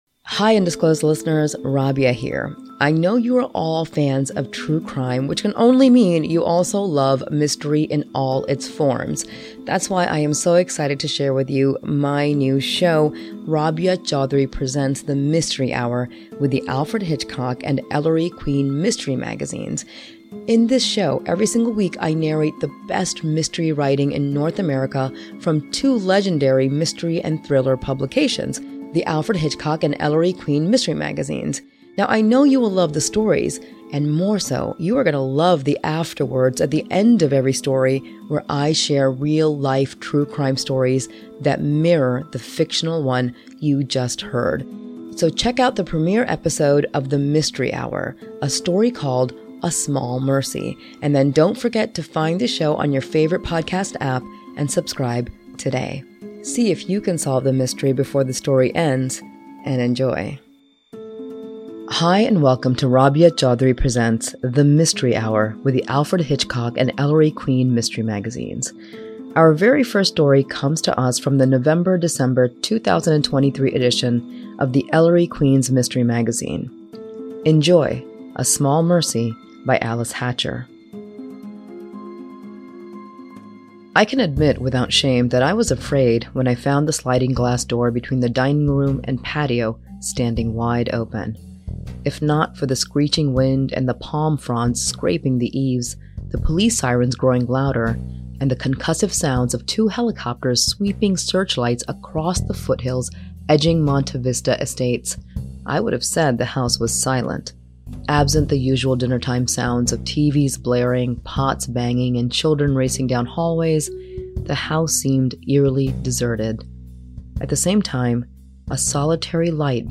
Aug 12, 2024, 09:22 PM Headliner Embed Embed code See more options Share Facebook X Subscribe Mystery Hour sees Rabia Chaudry partner up with award-winning, long-running suspense magazines. Ellery Queen's and Alfred Hitchcock's Mystery Magazines are working with Chaudry as she dives into and narrates contemporary crime-fiction stories evocative of the Master of Suspense.